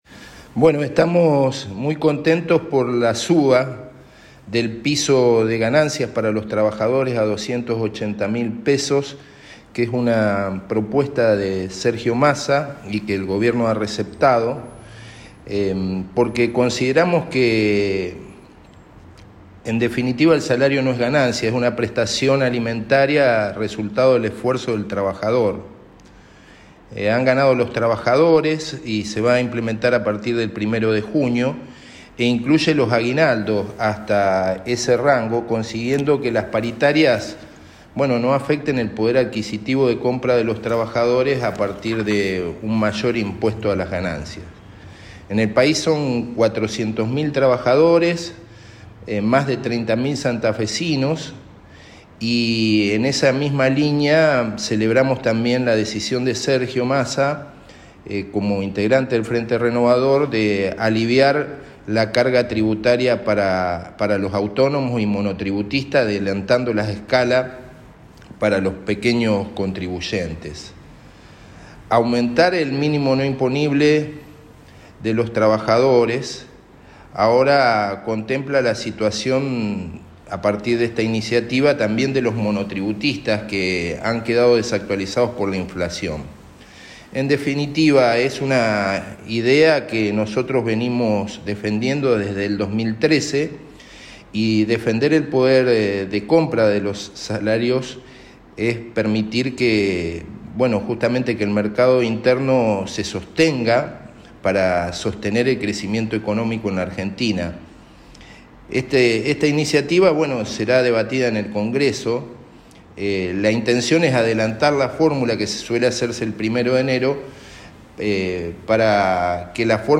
Al respecto, el diputado provincial Oscar Martínez, del Frente Renovador en Santa Fe, declaró lo siguiente: